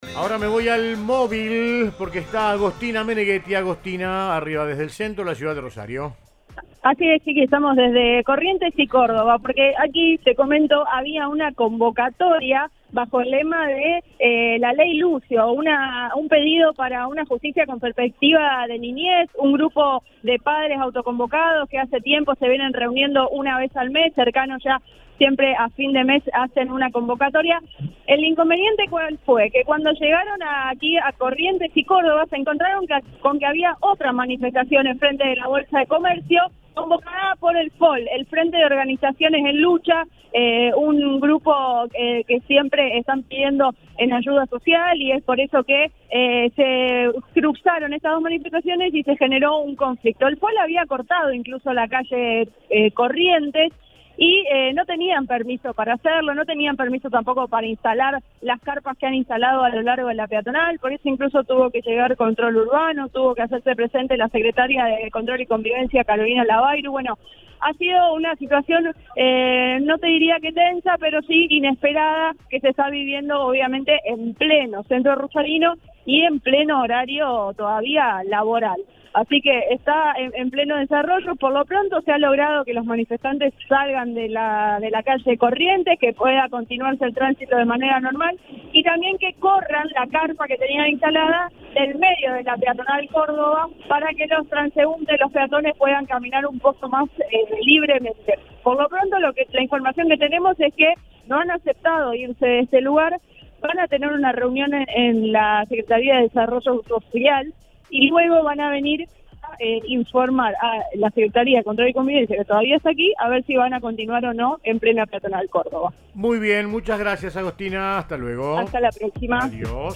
En diálogo con el móvil de Cadena 3 Rosario, desde la ONG Infancia Compartida contaron que su convocatoria se realizó “contra la obstrucción de vínculos y para promover el cuidado compartido en padres con conflictos judiciales”, y aclararon que “todos los 26 se conmemora el asesinato del niño Lucio Dupuy, que no es el único, es una situación que se replica”.